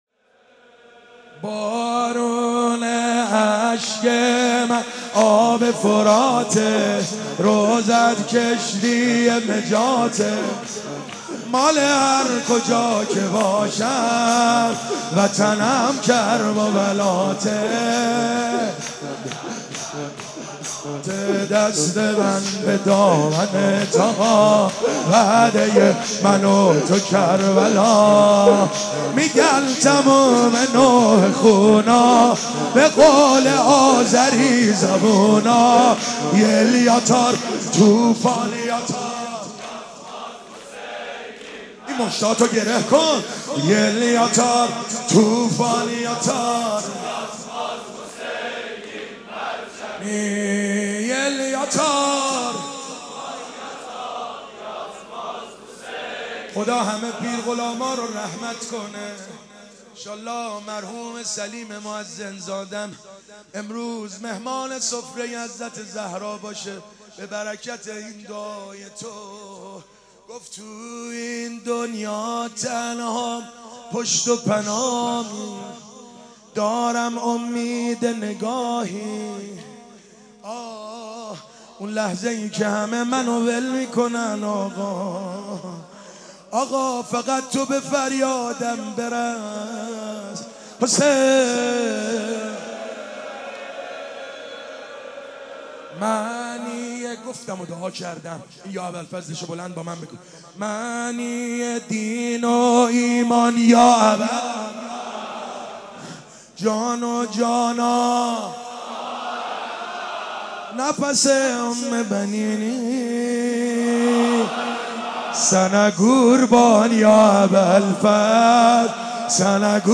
شور ٢.mp3